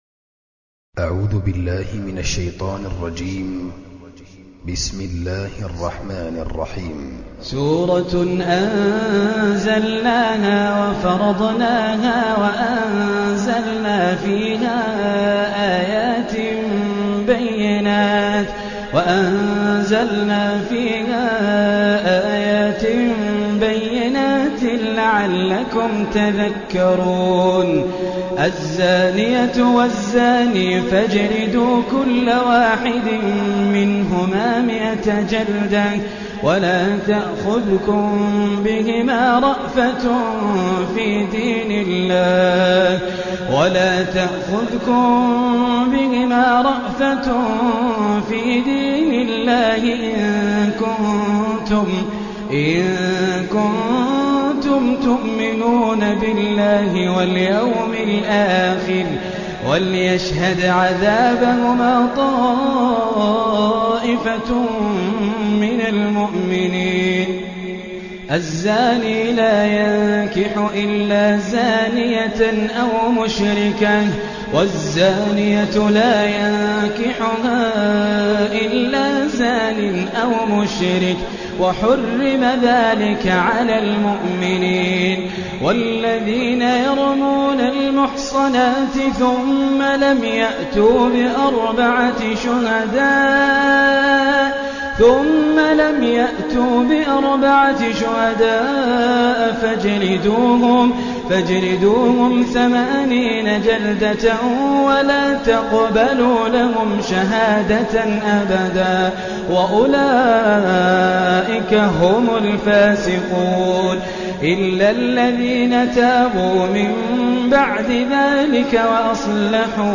Hafs an Asim